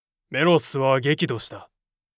パロディ系ボイス素材　2